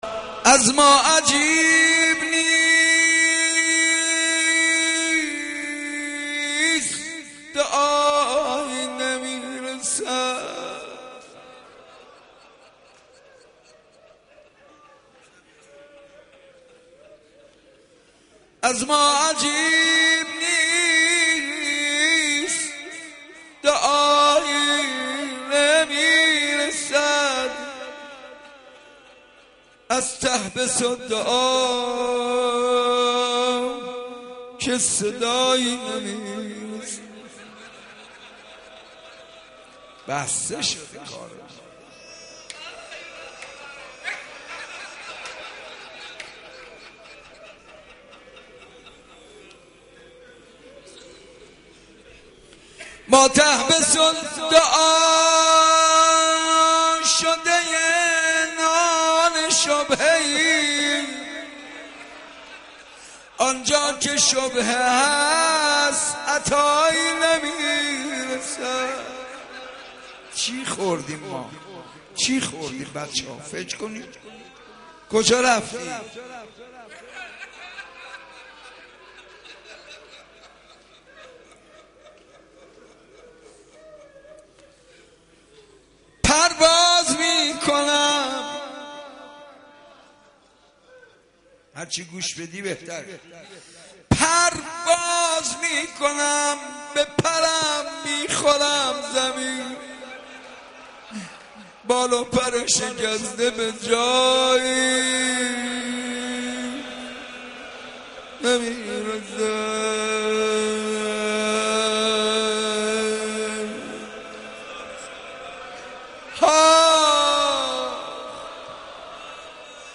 مناجات با خدا